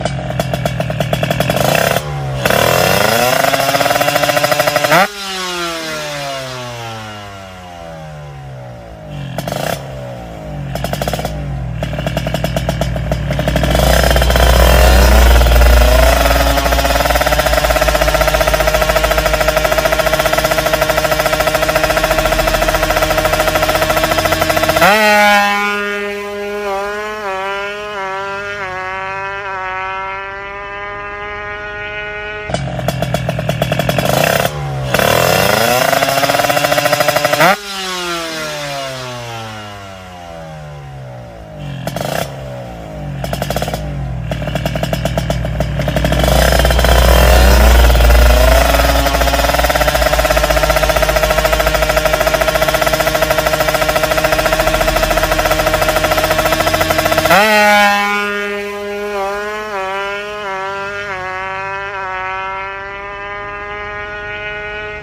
Suara Motor 2 Tak
Kategori: Suara Kendaraan
Keterangan: Suara Motor 2 Tak terkenal dengan bunyi khas yang nyaring dan berirama cepat, sering disebut sebagai suara cempreng.
suara-motor-2-tak-id-www_tiengdong_com.mp3